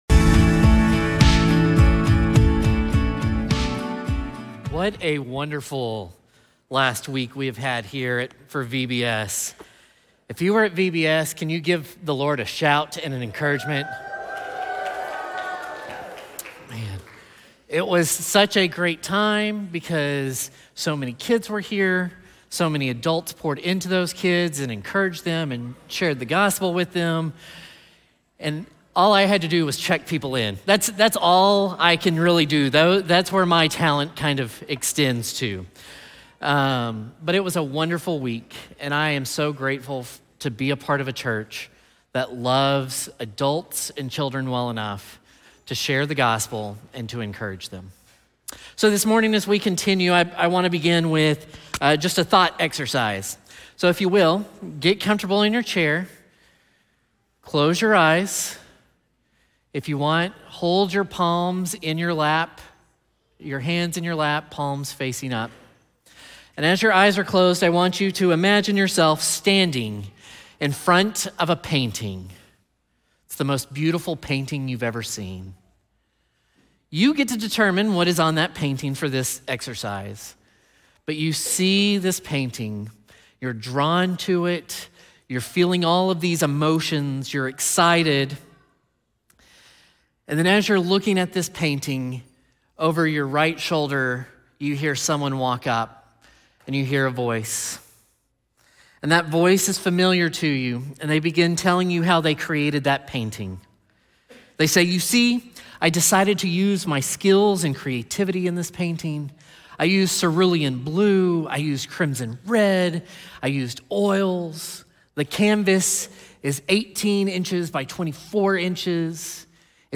This sermon emphasizes that God reveals Himself as Creator, Ruler, and most importantly, a loving Father who desires a deep, personal relationship with His children. Through faith in Jesus Christ, believers are not only saved but also adopted into God’s family, receiving full rights as heirs to His promises and blessings—not by works, but by grace.